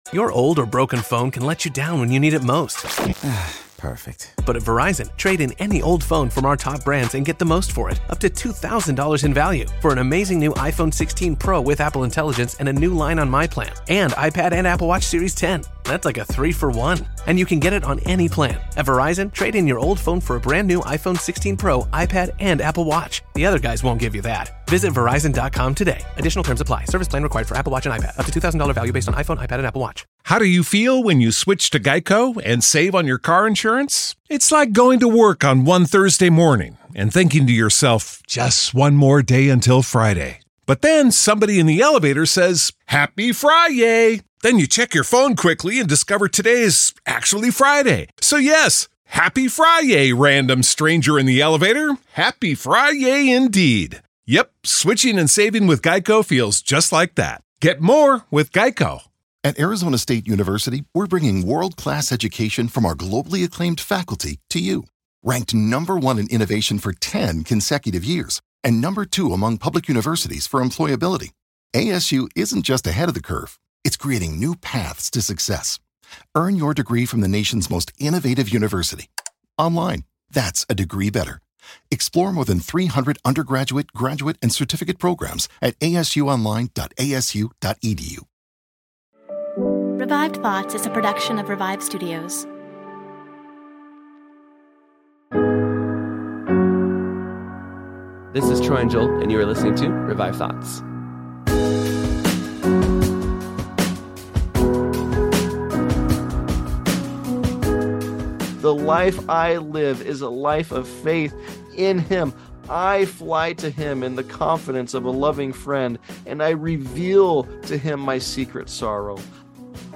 In this sermon he preached a New Year's sermon just after the death of his wife.<